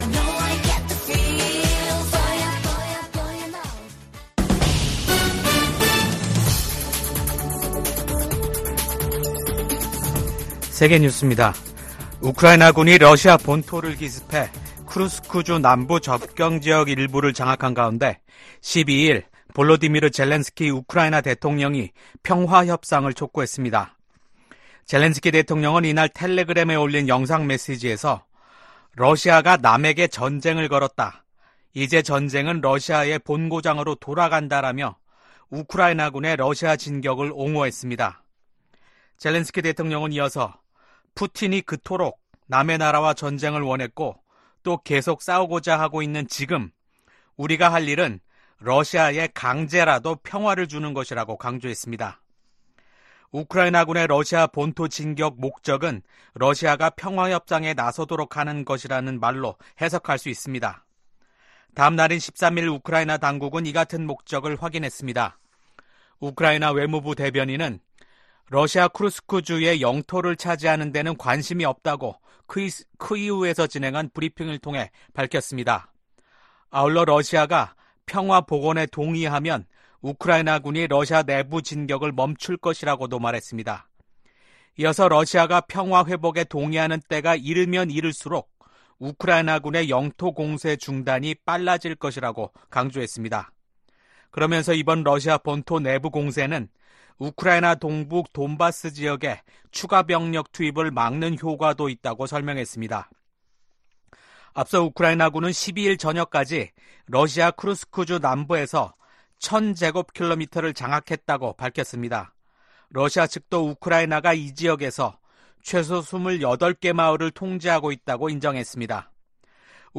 VOA 한국어 아침 뉴스 프로그램 '워싱턴 뉴스 광장' 2024년 8월 14일 방송입니다. 북러 군사 밀착이 우크라이나뿐 아니라 인도태평양 지역 안보에도 영향을 미칠 것이라고 미국 백악관이 지적했습니다.